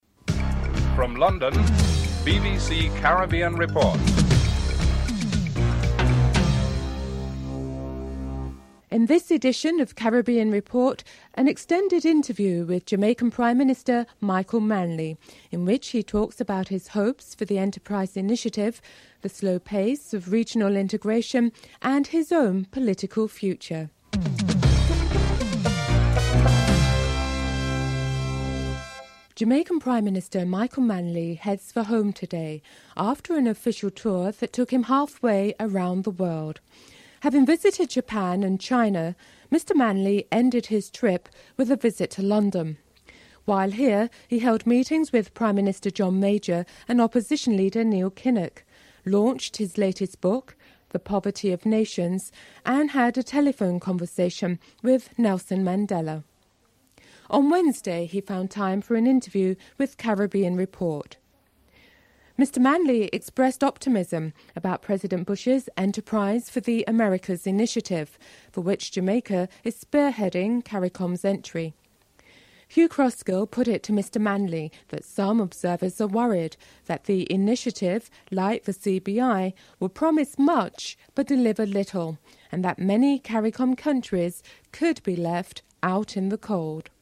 1. Headlines (00:00-00:29)